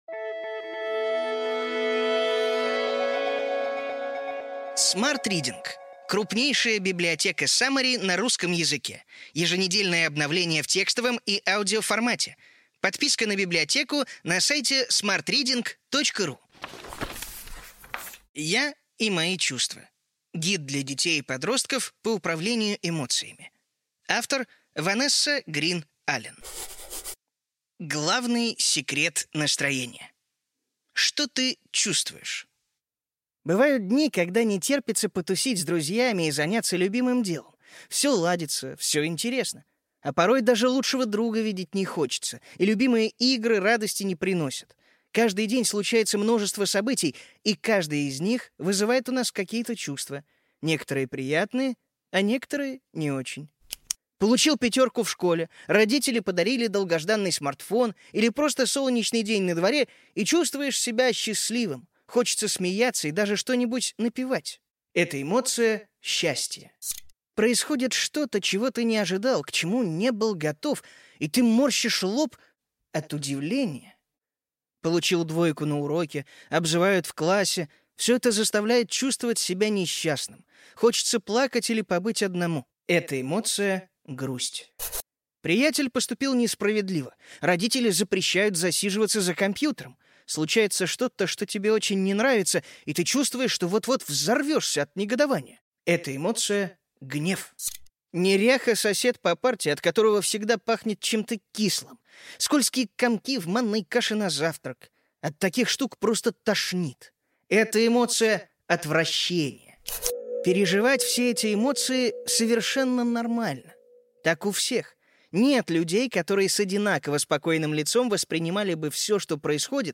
Аудиокнига Ключевые идеи книги: Я и мои чувства. Гид для детей и подростков по управлению эмоциями.